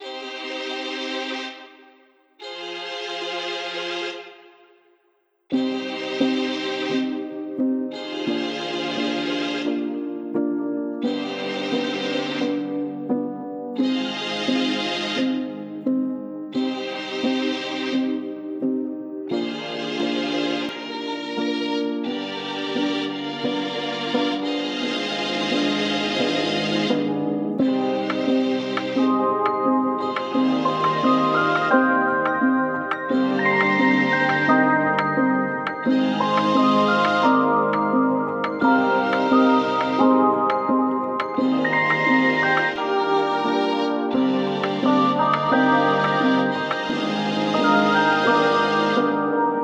Loop pack for dark melodies like Dark R&B, Trap, Dark Pop and more.
Classic synth tones that add that special and organic textures to your songs.
• High-Quality RnB Samples 💯